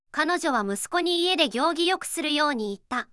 voicevox-voice-corpus